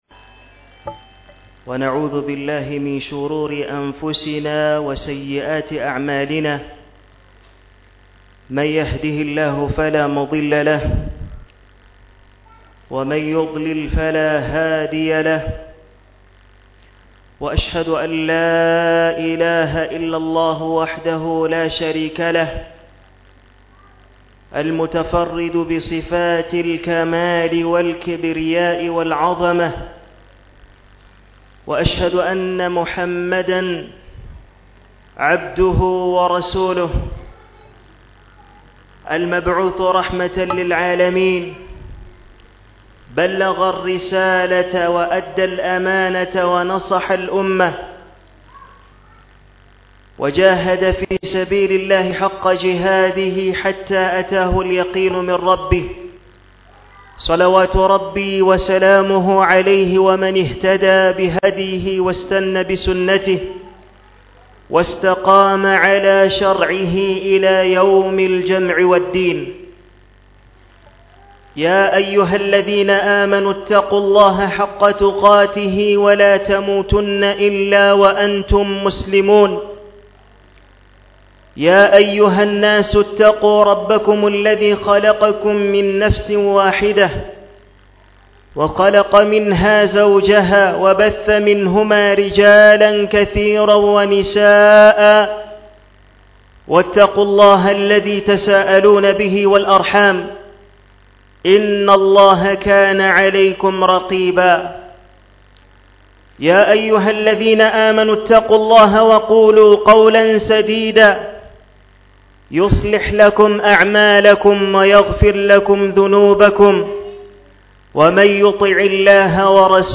JUMMA KHUTUB